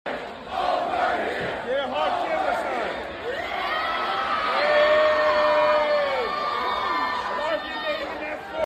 at GCWs ‘Holy Smokes’, Showboat-Atlantic City, NJ, Spring 2023